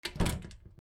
豪邸の玄関扉を閉める 弱 02